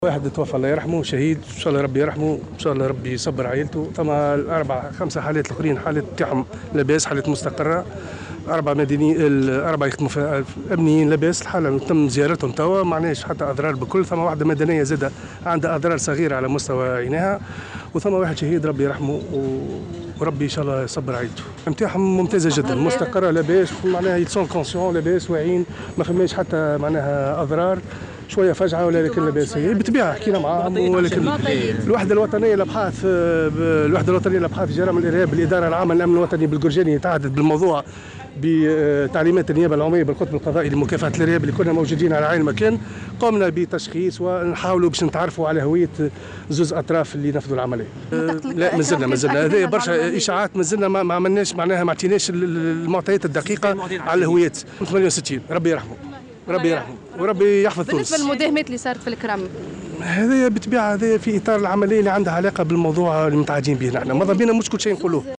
وأضاف في تصريح لمراسلة "الجوهرة أف أم" أنه تمّ تكليف الوحدة الوطنيّة لمكافحة الإرهاب بالقرجاني بمتابعة الموضوع بالتعاون مع المخابر الجنائيّة وذلك للتعرّف على هويّة العنصرين اللذين قاما بتفجير نفسيهما.